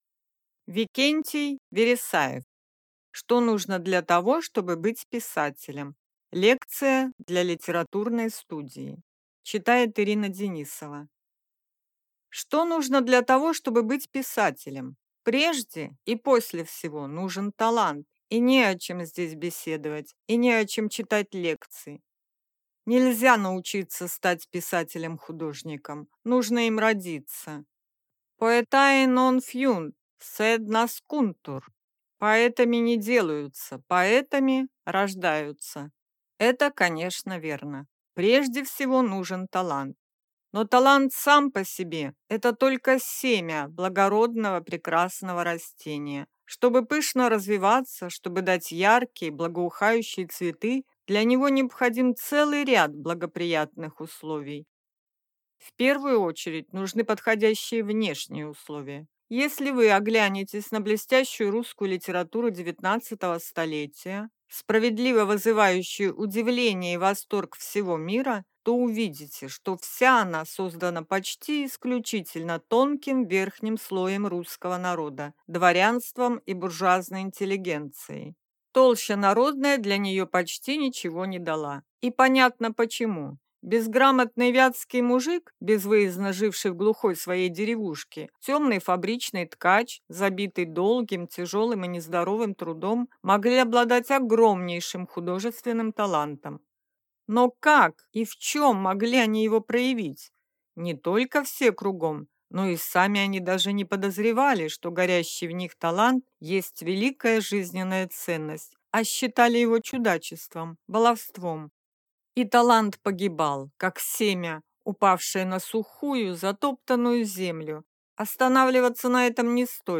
Аудиокнига Что нужно для того, чтобы быть писателем?